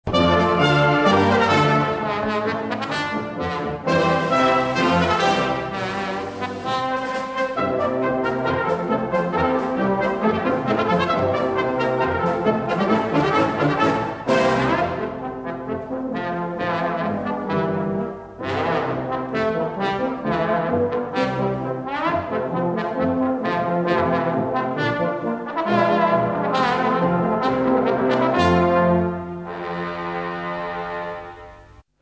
The trombone music fitted this drama perfectly.